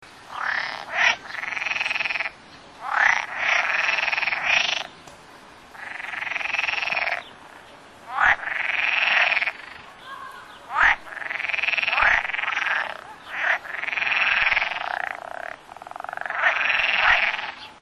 Kleiner Wasserfrosch
Hören Sie sich hier ein Wasserfroschkonzert an:
Rufe vom kleinen Wasserfrosch
rufe_kleiner_wasserfrosch_froschnetz.ch_.mp3